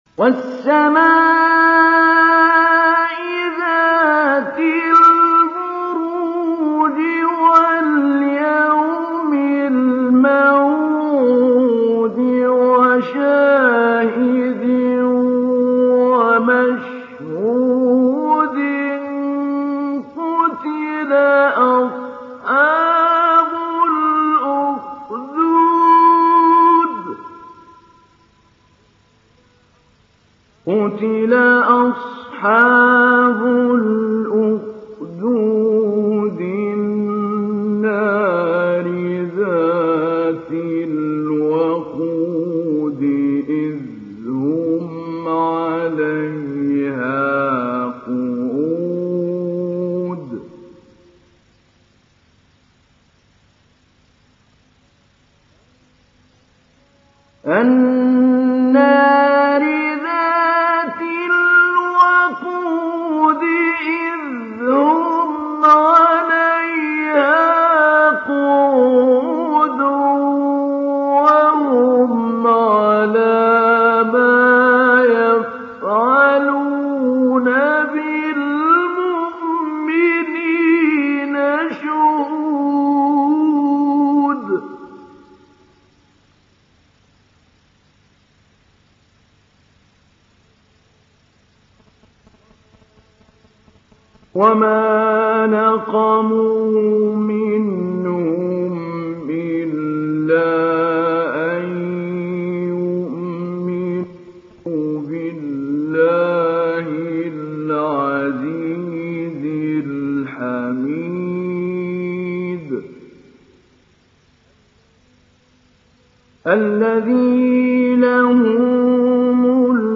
تحميل سورة البروج mp3 بصوت محمود علي البنا مجود برواية حفص عن عاصم, تحميل استماع القرآن الكريم على الجوال mp3 كاملا بروابط مباشرة وسريعة
تحميل سورة البروج محمود علي البنا مجود